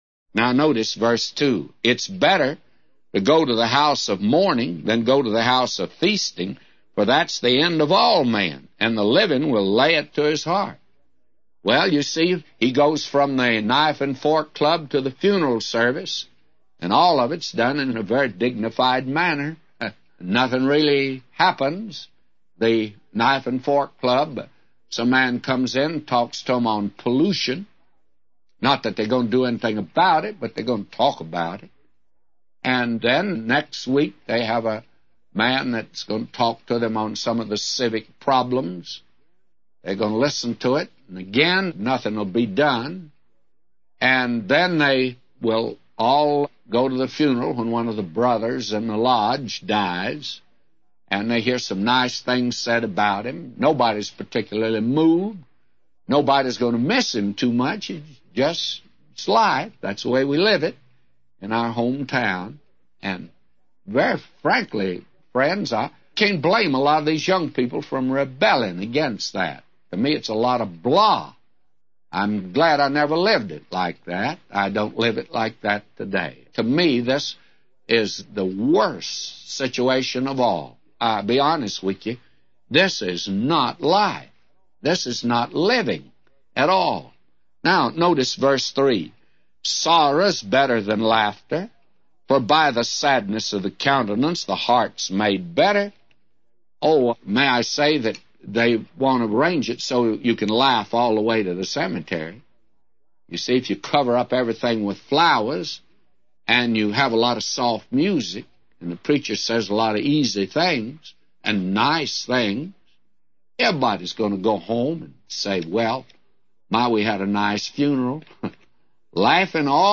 A Commentary By J Vernon MCgee For Ecclesiastes 7:2-999